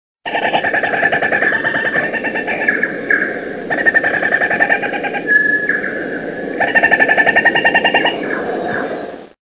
If/when you see it again, try to hear its call, very distinct, nothing like anything you would have heard before...and the red 'lone ranger 'Zorro' over the eyes.
This sort of sounds right...It is taken in the bush so has more of an echo sound
There is also a brief double chirp of another bird non kakatiki in the background
The best way to describe is a machine gun
kakariki.mp3